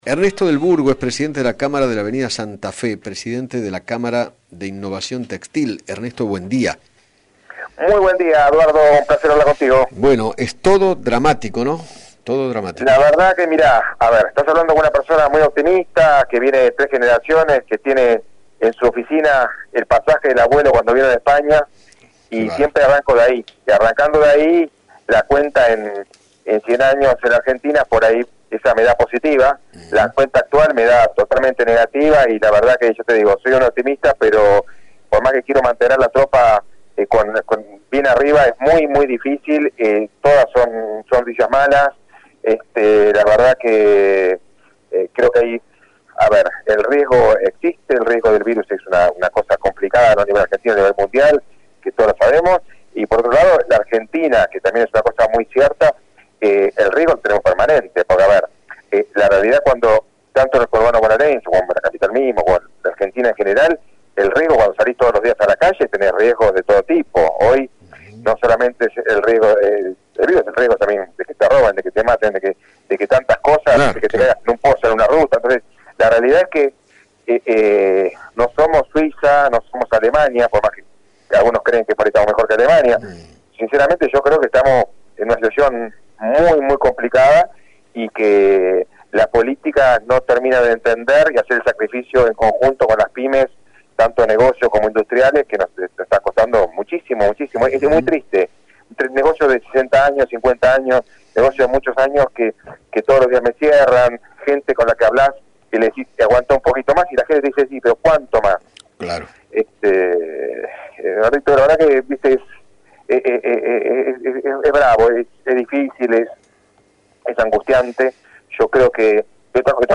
dialogó con Eduardo Feinmann sobre el momento que están pasando los locales de aquella zona desde que comenzó el aislamiento.